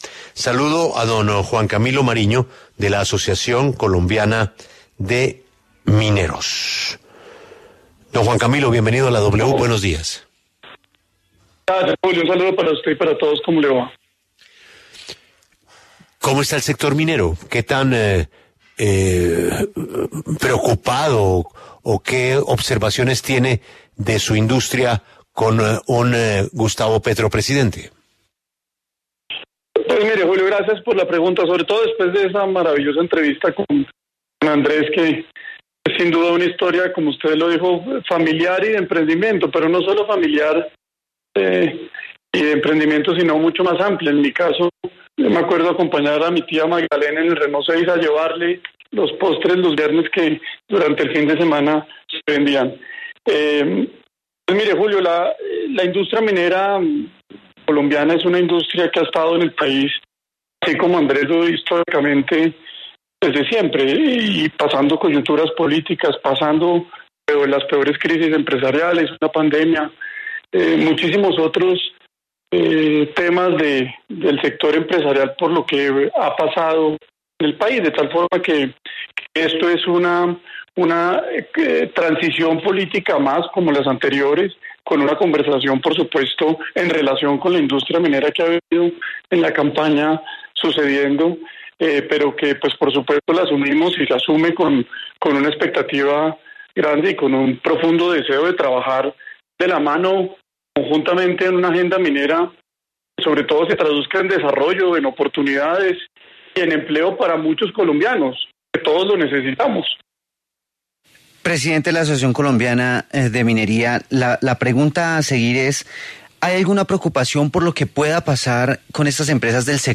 El sector minero del país ha sido uno de los que más incertidumbre ha mostrado frente a la elección de Gustavo Petro como presidente de la República, por ende en entrevista con La W, la Asociación Colombiana de Minería, aseguró que los mineros colombianos han tenido que pasar por diferentes situaciones, tanto políticas, económicas como sociales y que, en este momento, la que se registra es una nueva transición política a la que se le tendrá que hacer frente, mostrando total disponibilidad de trabajar con el nuevo gobierno para trazar una agenda minera.